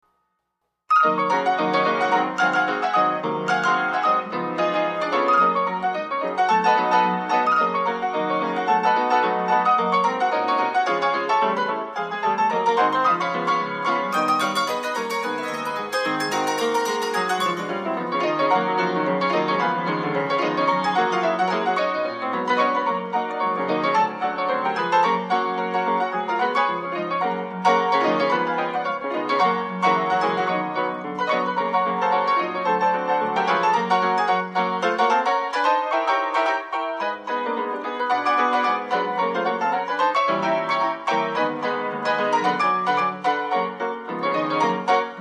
Wurlitzer "O"